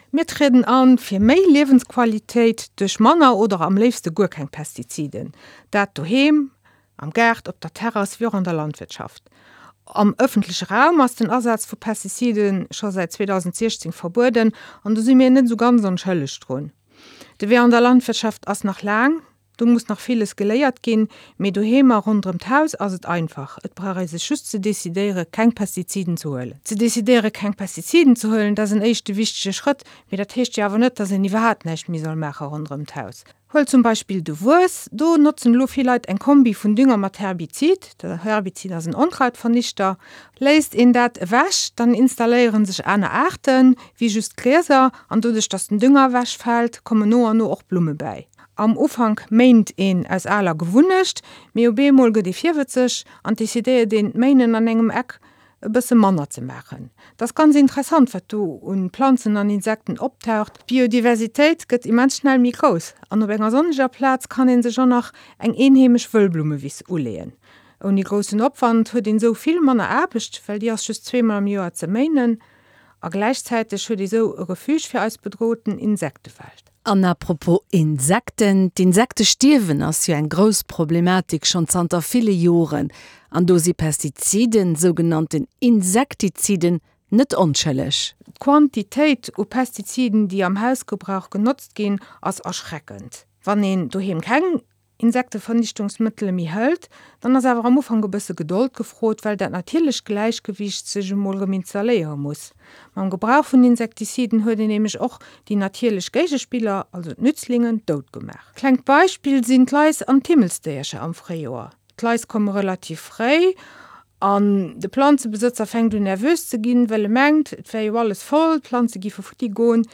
Interview um Radio 100,7 – E schéine Gaart ouni Pestiziden (02/09/20):